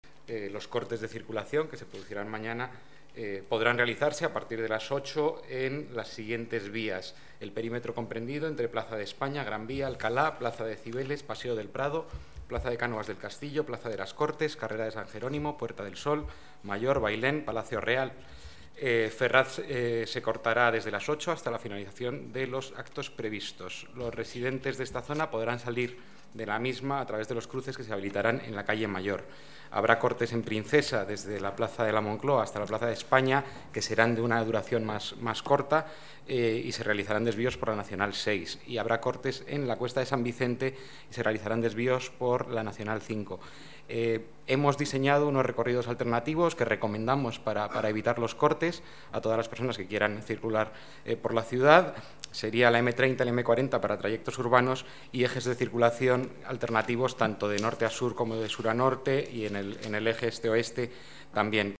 Nueva ventana:Declaraciones delegado Medio Ambiente y Movilidad, Diego Sanjuanbenito: dispositivo tráfico proclamación Felipe VI